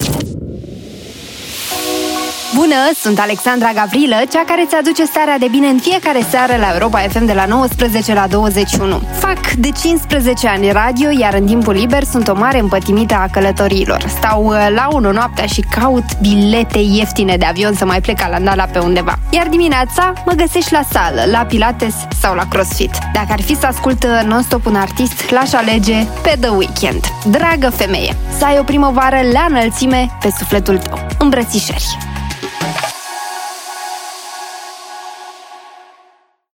Câteva dintre mesajele colegelor noastre: